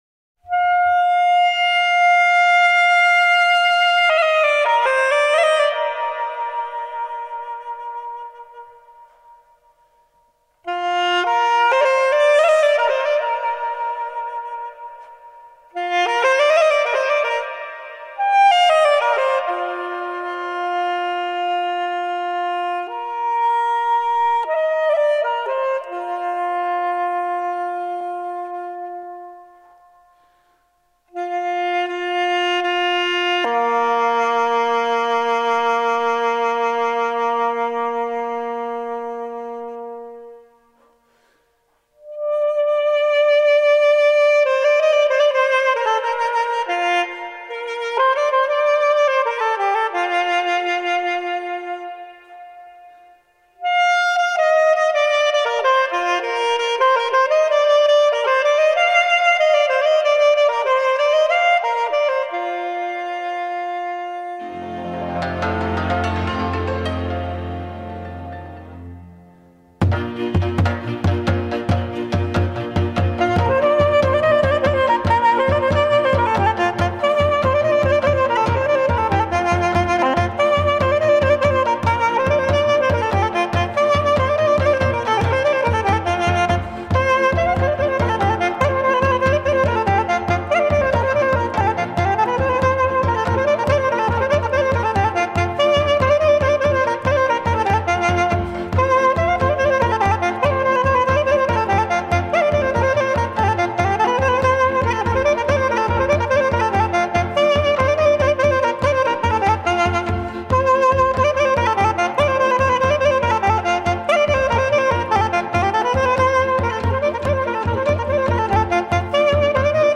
cu acompaniamentul orchestrei